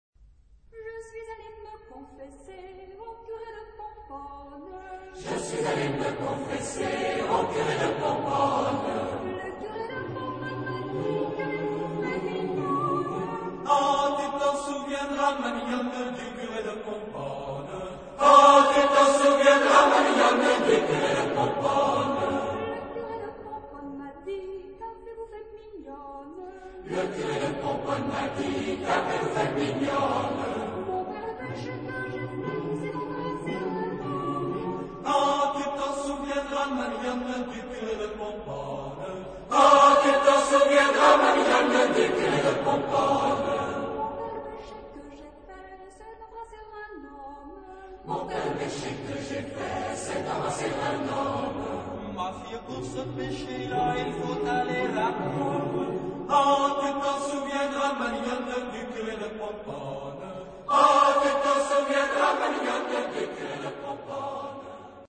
Género/Estilo/Forma: Tradicional ; Fantasía ; Profano
Carácter de la pieza : pillo
Tipo de formación coral: SATB  (4 voces Coro mixto )
Solistas : 1 au choix  (1 solista(s) )
Tonalidad : fa sostenido menor